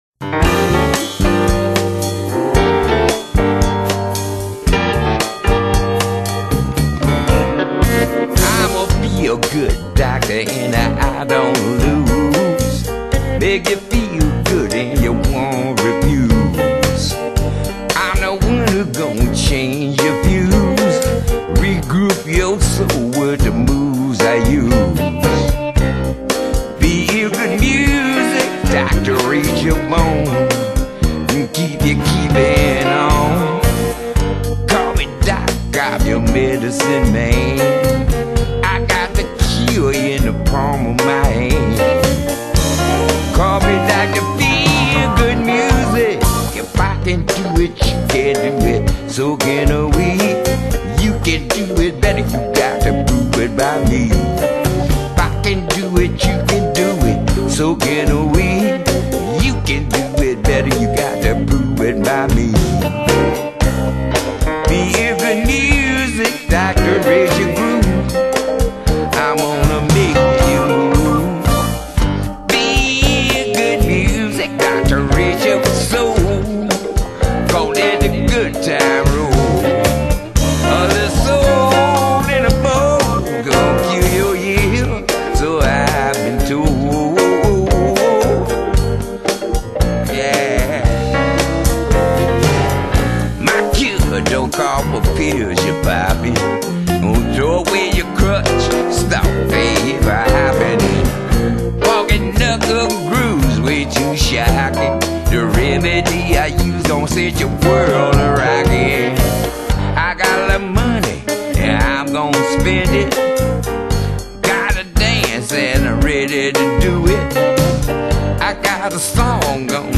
音樂風格︰Jazz / Blues | 1CD |
这张专辑音乐的节奏舒缓旋律优美的布鲁斯音乐，歌手的唱法有点特别！